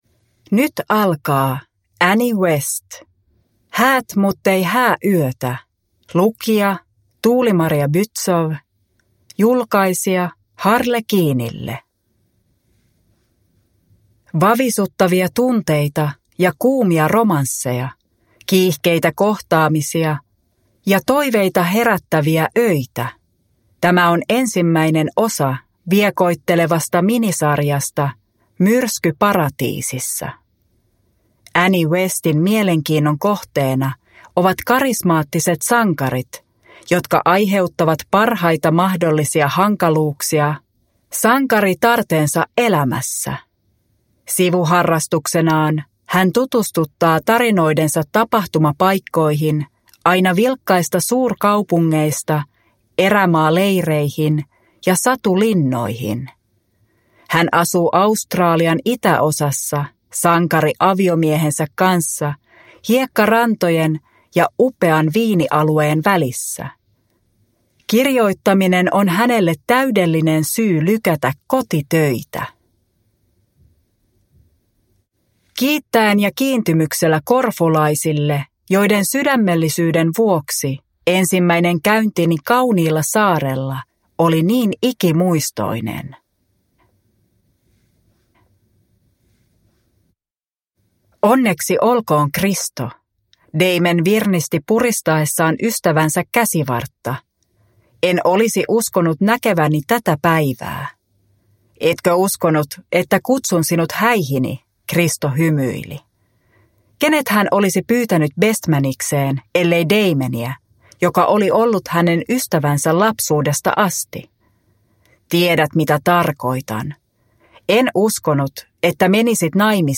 Häät muttei hääyötä – Ljudbok – Laddas ner